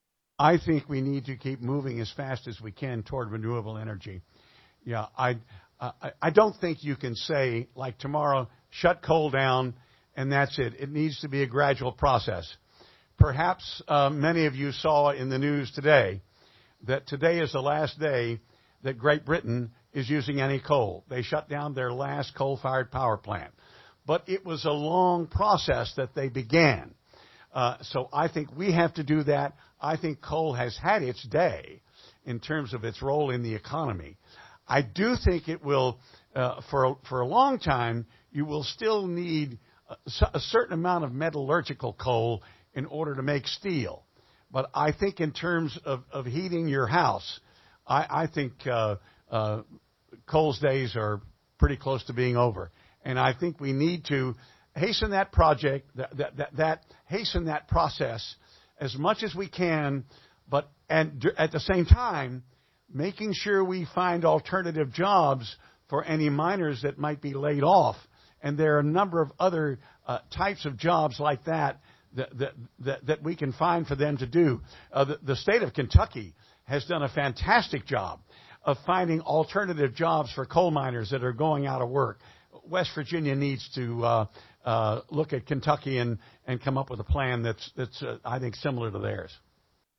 Jefferson Co. Senate Candidates Debate Education, Environment, Abortion - West Virginia Public Broadcasting